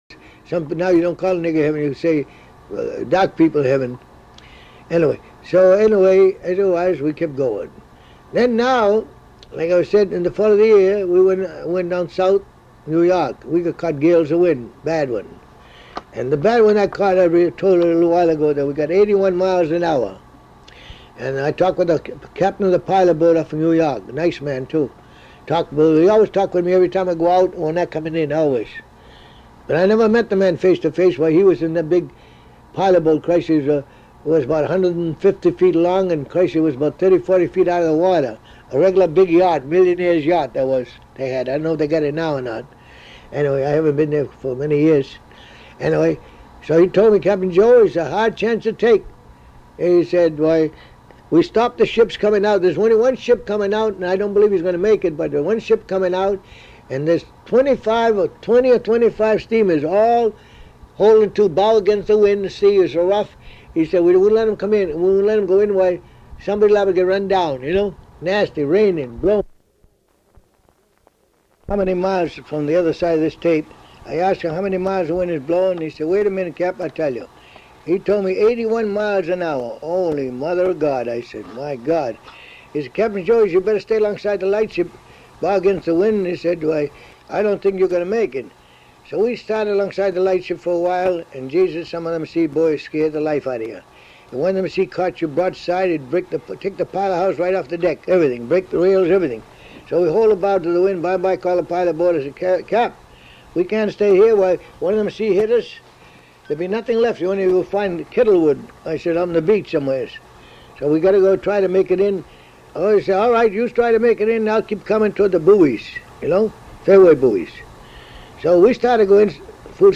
His mailman took the trashed tapes, spliced them, and made copies.